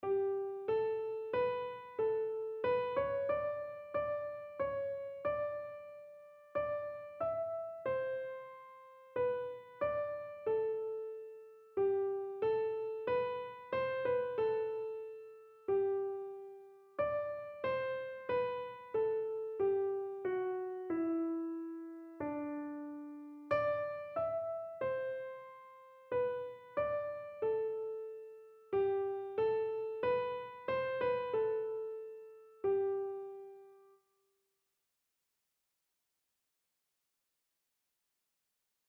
Einzelstimmen (Unisono)
• Sopran [MP3] 608 KB